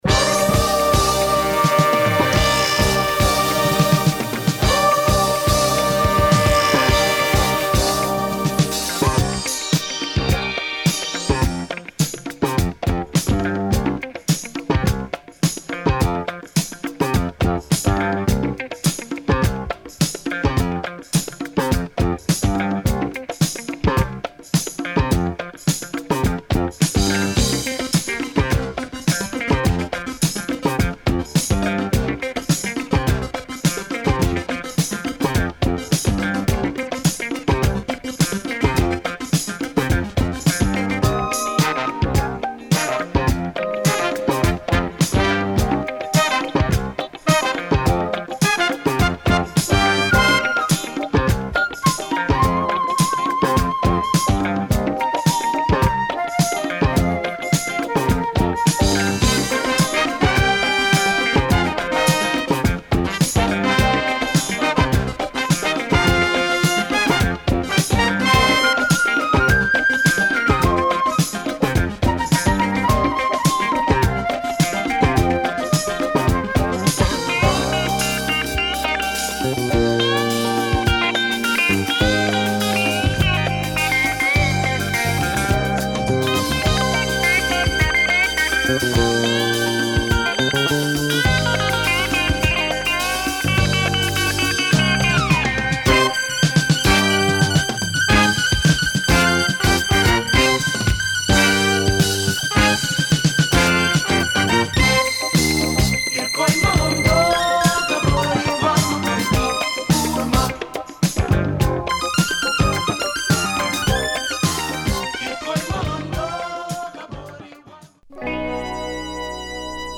Super funky / afro disco anthem